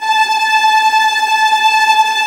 VIOLINT BN-L.wav